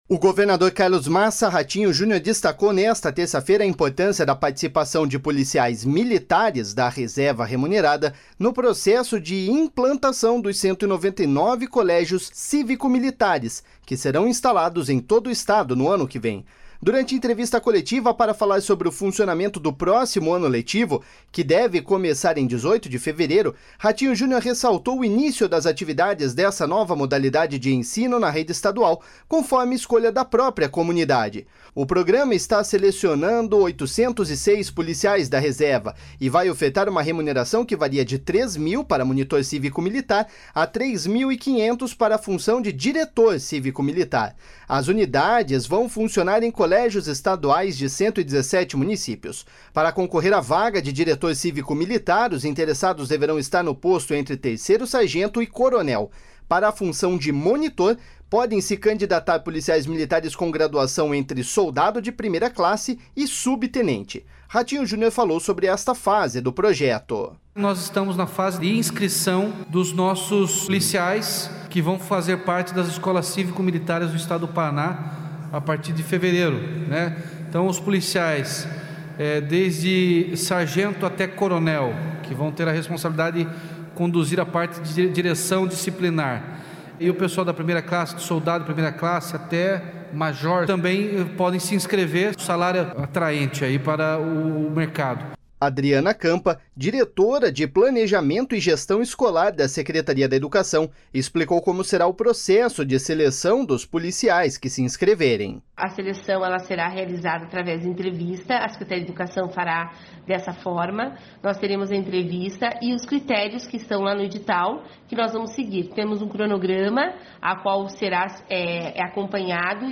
Durante entrevista coletiva para falar sobre o funcionamento do próximo ano letivo, que deve começar em 18 de fevereiro, Ratinho Junior ressaltou o início das atividades desta nova modalidade de ensino na rede estadual, conforme escolha da própria comunidade. O programa está selecionando 806 policiais da reserva e vai ofertar uma remuneração que varia de R$ 3 mil, para monitor cívico-militar, a R$ 3,5 mil, para a função de diretor cívico-militar.
Ratinho Junior falou sobre esta fase do projeto.// SONORA RATINHO JUNIOR.//